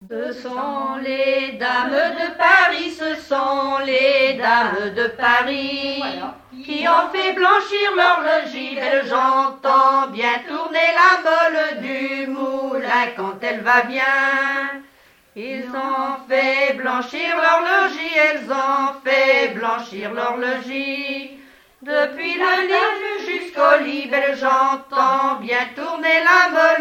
Genre laisse
Témoignages sur la pêche, accordéon, et chansons traditionnelles
Pièce musicale inédite